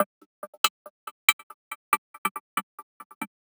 tx_synth_140_tight_GCD1.wav